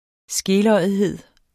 Udtale [ ˈsgelʌjəðˌheðˀ ]